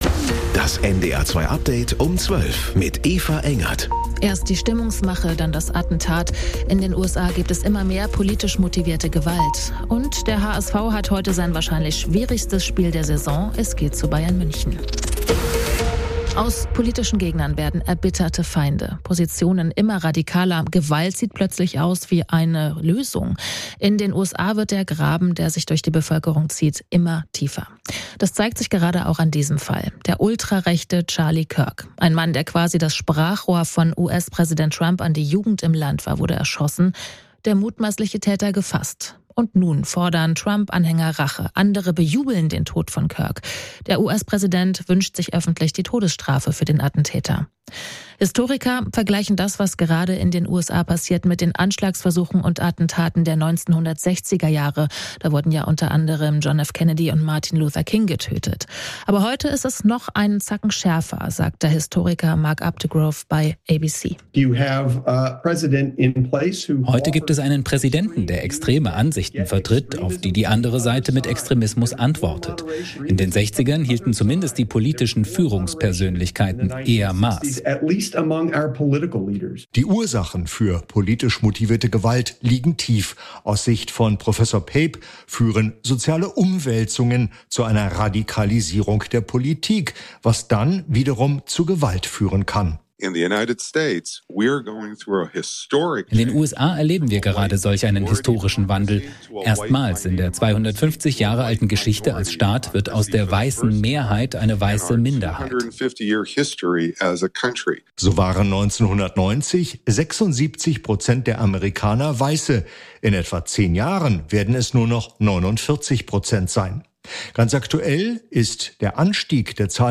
NDR 2 Tägliche Nachrichten Nachrichten NDR News Kurier Um 12 Update Um 12